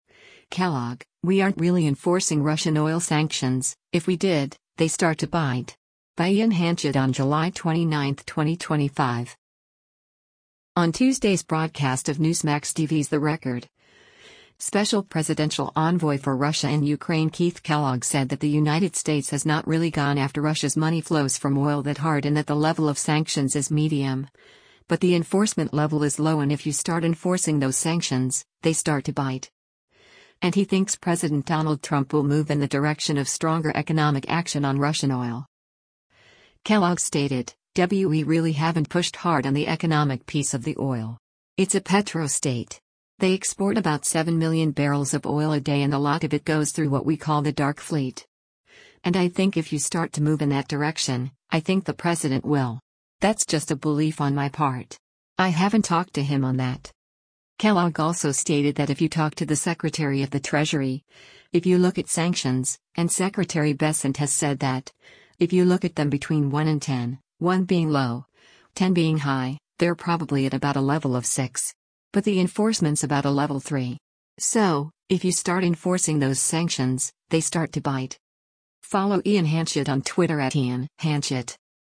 On Tuesday’s broadcast of Newsmax TV’s “The Record,” Special Presidential Envoy for Russia and Ukraine Keith Kellogg said that the United States has not really gone after Russia’s money flows from oil that hard and that the level of sanctions is medium, but the enforcement level is low and “if you start enforcing those sanctions, they start to bite.”